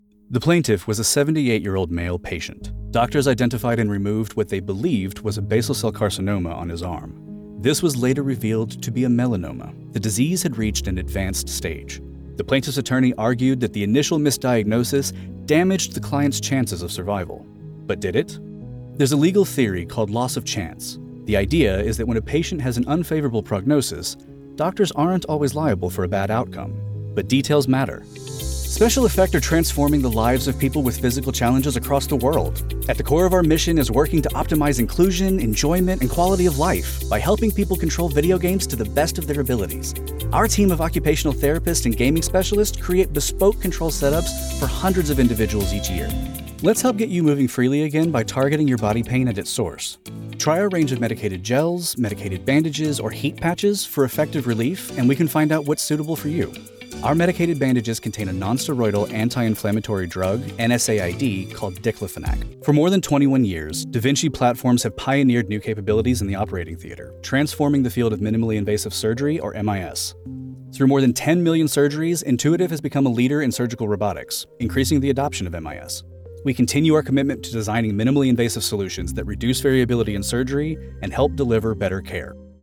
Professional male voiceover artist for your next project!
Corporate Narration Demo
My broadcast-quality home studio setup includes:
• Microphone: AKG Pro C214 XLR
• Sound Treatment: 2-inch acoustic foam panels and bass traps